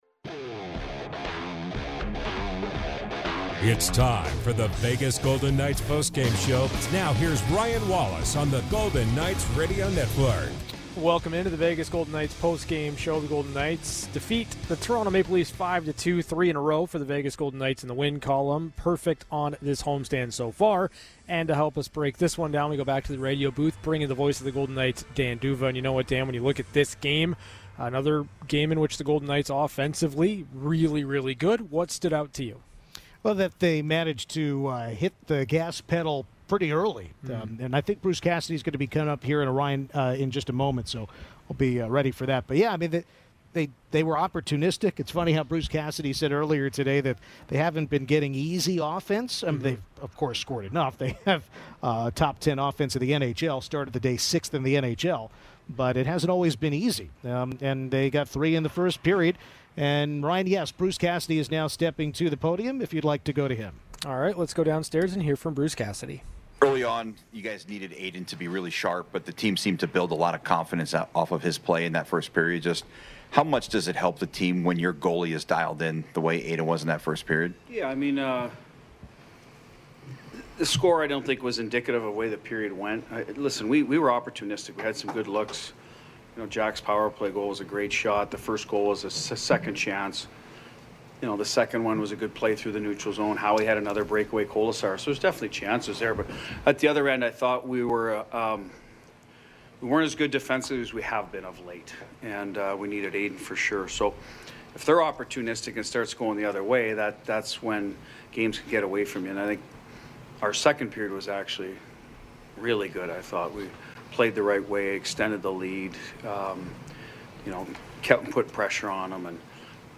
VGK Postgame - VGK POSTGAME SHOW 03/05